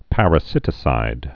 (părə-sĭtĭ-sīd)